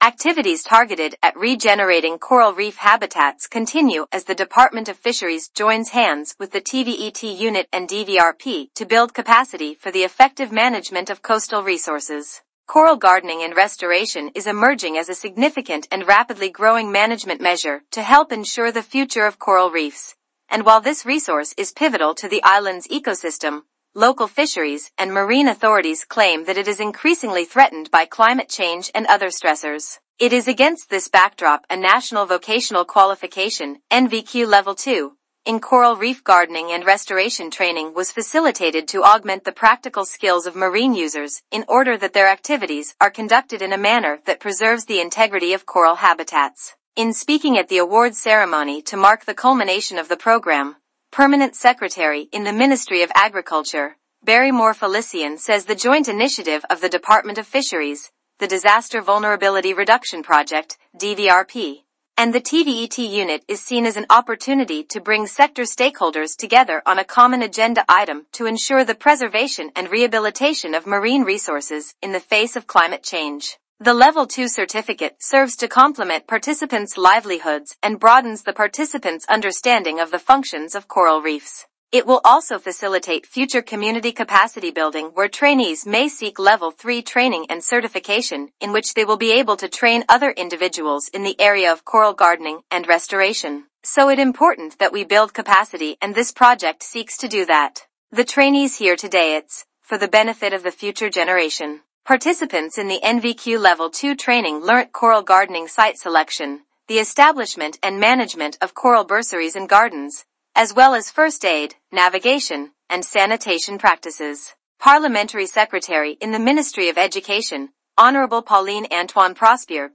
Play Press Release
In speaking at the Award Ceremony to mark the culmination of the Program, Permanent Secretary in the Ministry of Agriculture, Barrymore Felicien says the joint initiative of the Department of Fisheries, the Disaster Vulnerability Reduction Project (DVRP) and the TVET Unit is seen as an opportunity to bring sector stakeholders together on a common agenda item to ensure the preservation and rehabilitation of marine resources in the face of climate change.